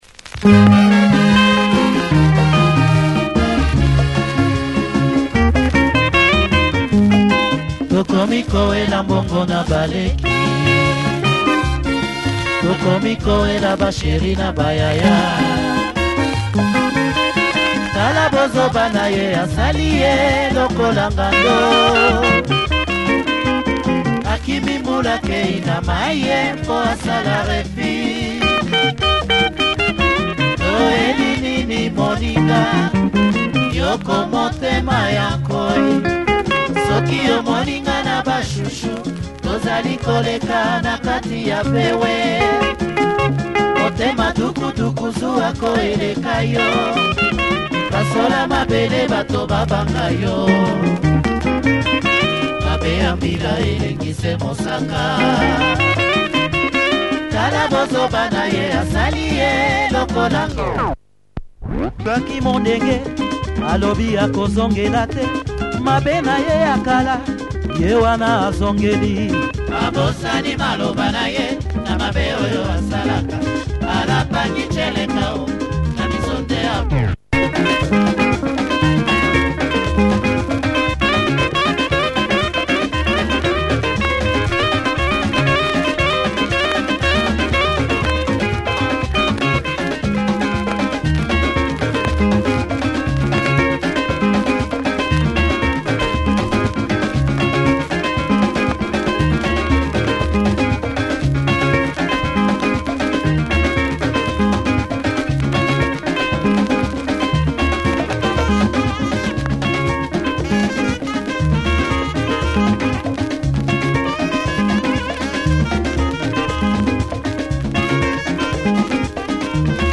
Nice Lingala track with great backing and vocals.
Check soundclip a definite party number, lift off in part-2